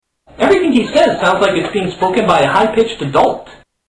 High pitched adult